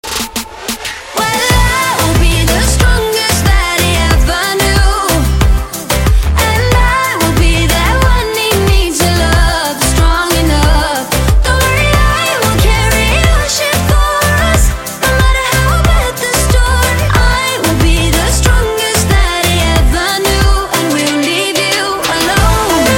• Качество: 128, Stereo
зажигательные
заводные